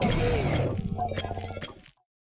Cri de Paume-de-Fer dans Pokémon Écarlate et Violet.